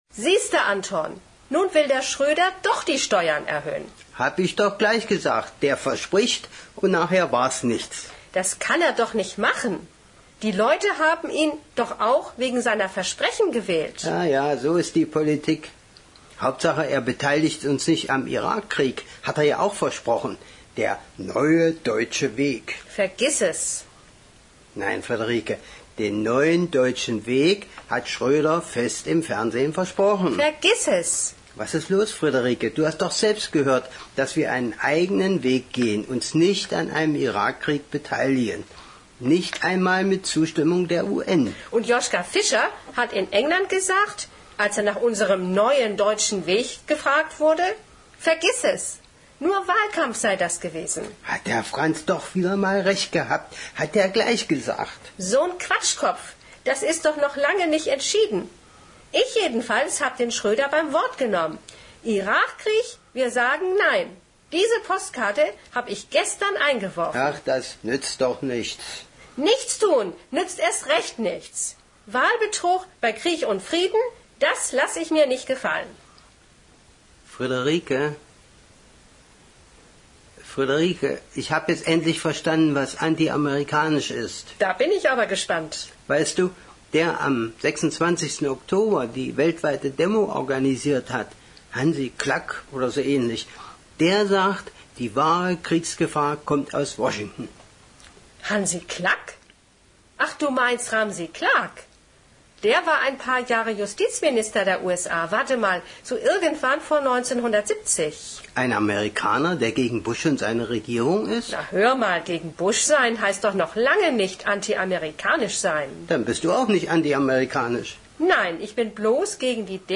Bei den älteren Audio-Dateien wird vor dem Hördialog kurz die damalige politische Lage geschildert.